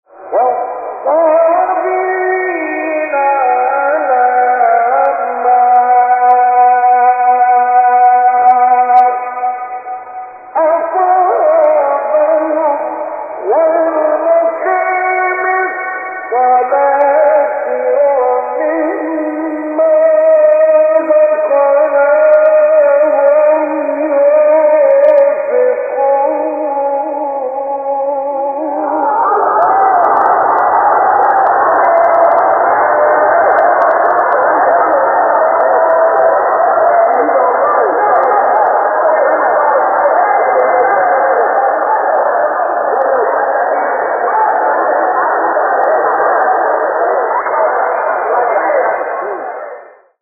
آیه 35 سوره حج استاد مصطفی اسماعیل | نغمات قرآن | دانلود تلاوت قرآن